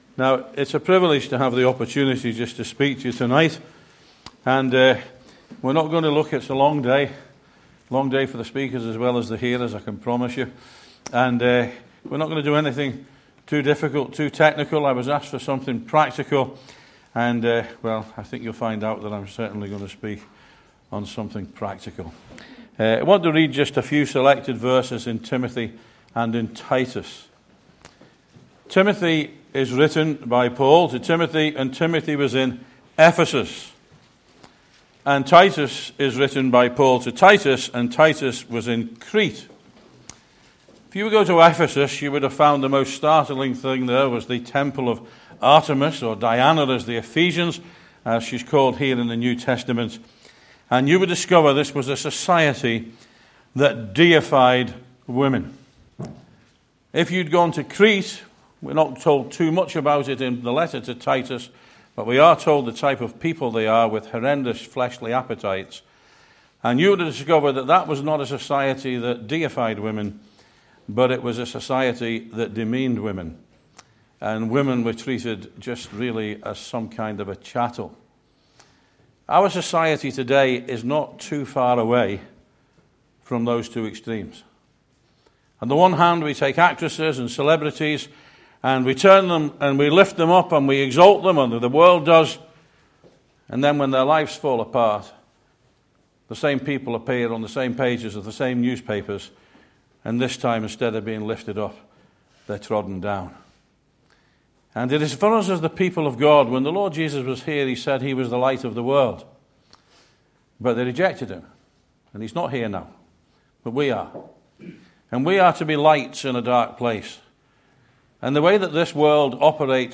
EASTER CONF 2025: